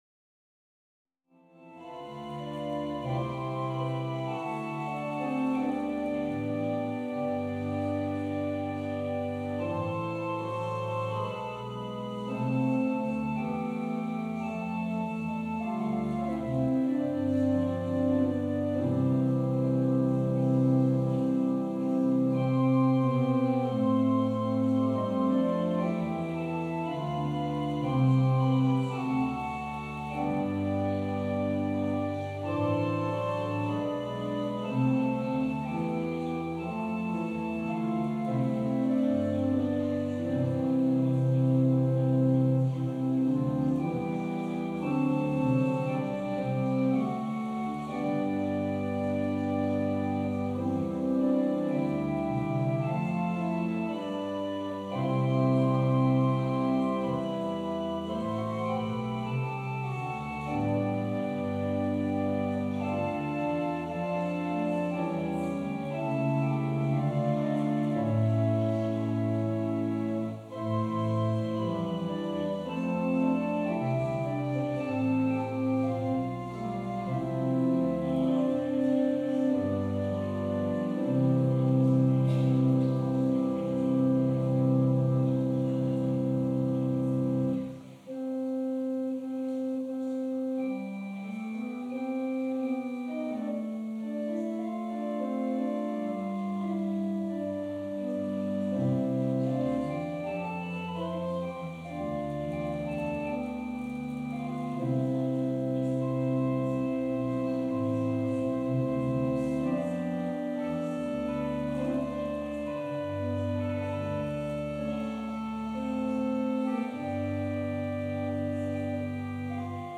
Passage: John 16: 12-15 Service Type: Sunday Service Scriptures and sermon from St. John’s Presbyterian Church on Sunday